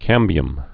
(kămbē-əm)